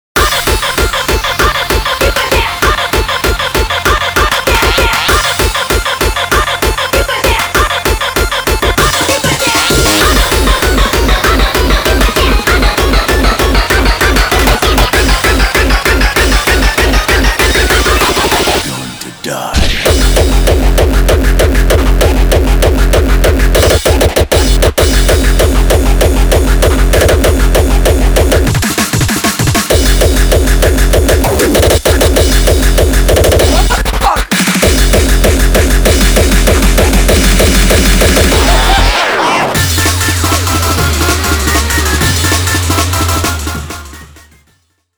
種別/サイズ 音楽CD - テクノ/ ＣＤ－ＲＯＭ 13p